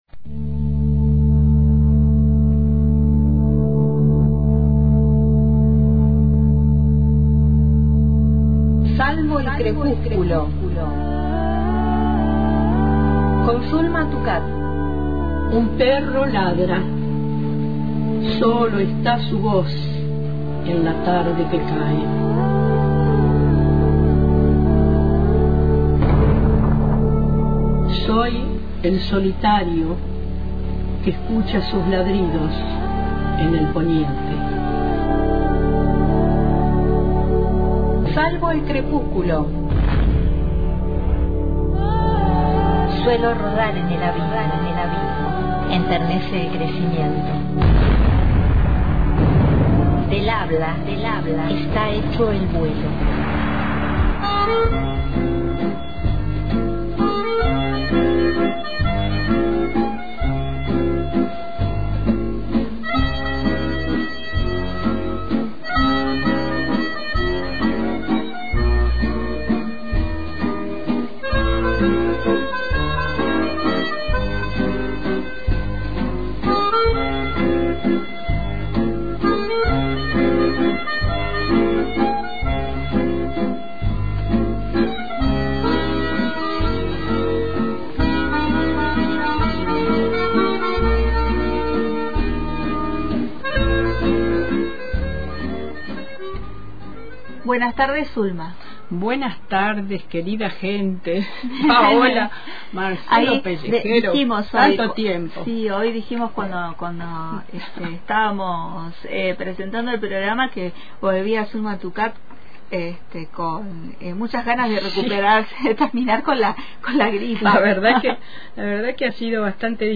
En una nueva emisión radial